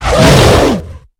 hit3.ogg